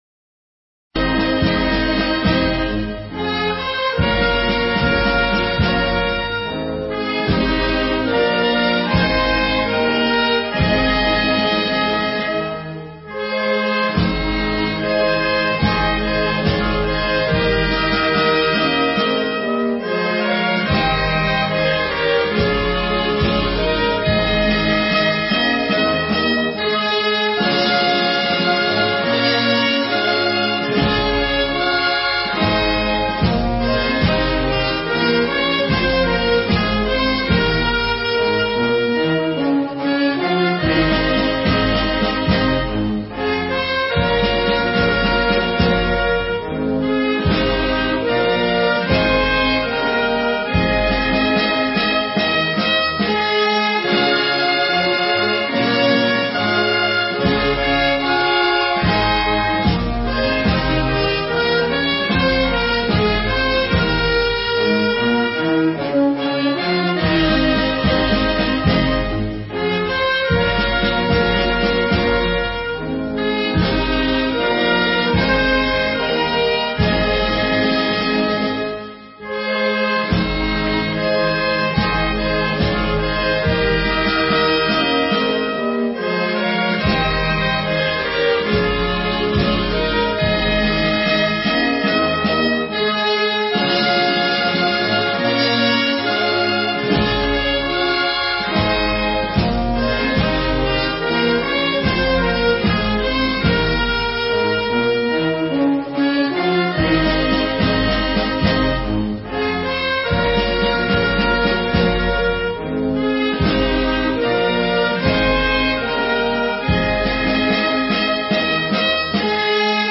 File nhạc không lời